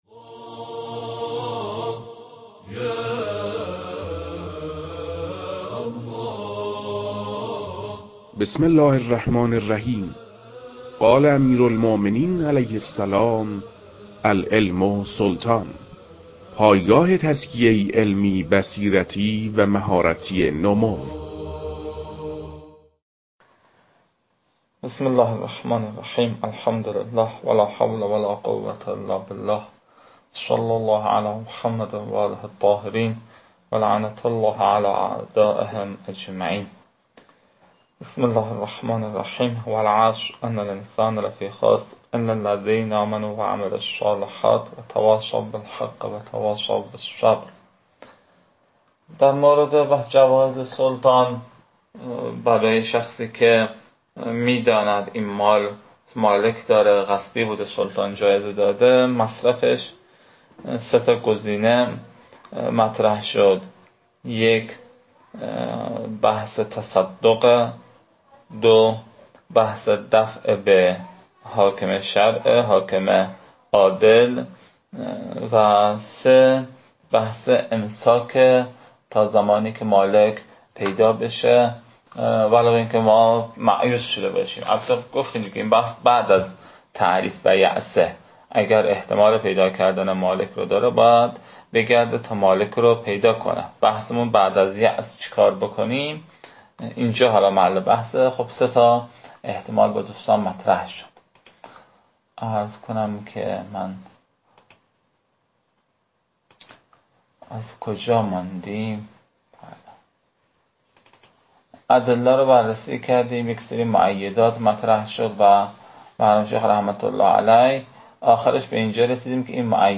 در این بخش، فایل های مربوط به تدریس مبحث المسألة الثانية جوائز السلطان و عمّاله از خاتمه كتاب المكاسب متعلق به شیخ اعظم انصاری رحمه الله